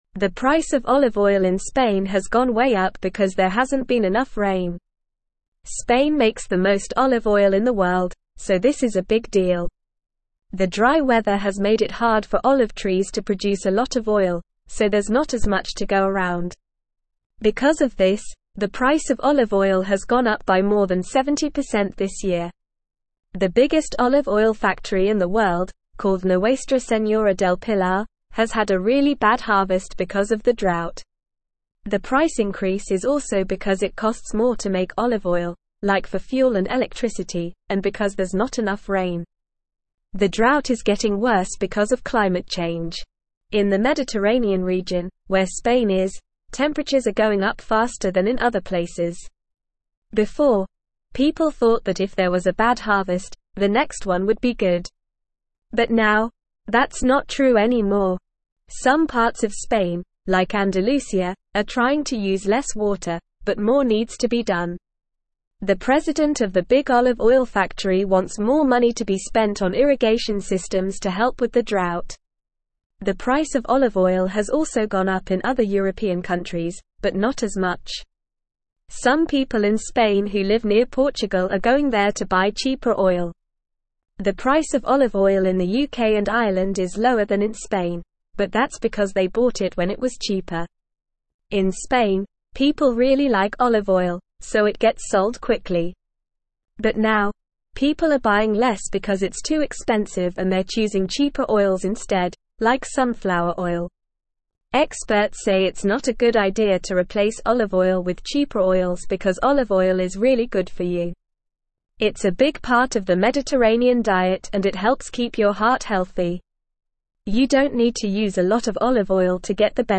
Normal
English-Newsroom-Upper-Intermediate-NORMAL-Reading-Skyrocketing-Olive-Oil-Prices-in-Spain-Due-to-Drought.mp3